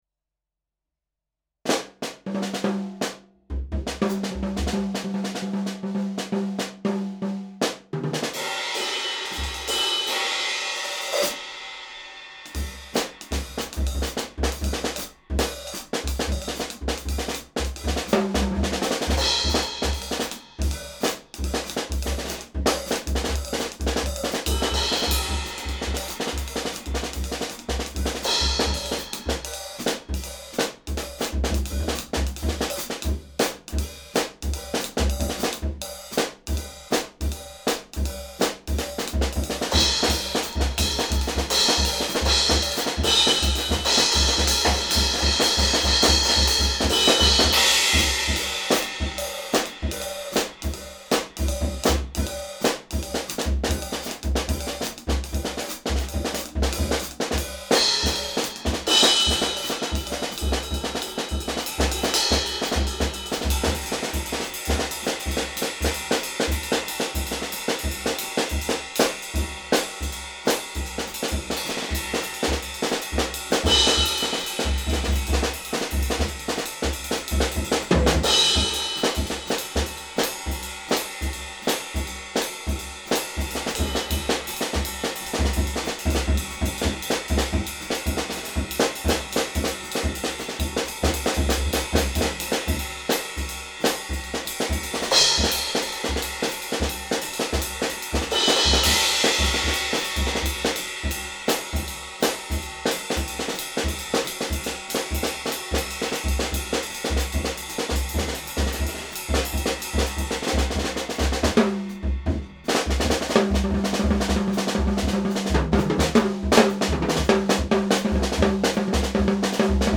I have my own studio, which is just a sound treated bedroom reserved for music recordings.
The last track I recorded is an improvised Jazz drum solo. The panning and such isn’t exactly as I want it to be.
I have an X-Y-Z setup and it destroyed the mix. The result is that you don’t have a proper representation of the setup of my drumset anymore.
The hi-hat is barely audible when closed by foot. The bass drum also still has a dull thump.
“A Bit of Jazz” (Jazz drumsolo)